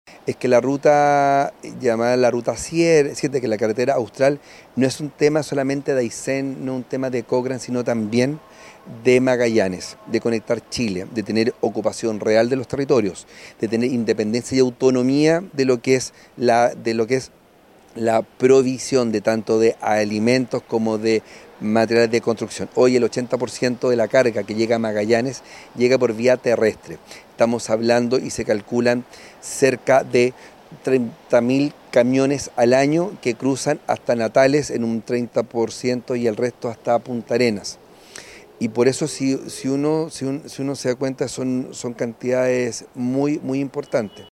El alcalde de Punta Arenas, capital regional de Magallanes, Claudio Radonich, también reiteró la importancia del transporte de carga que se desplaza por Argentina, lo que a su vez es indispensable para el abastecimiento de la zona más austral del país.